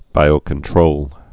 (bīō-kən-trōl)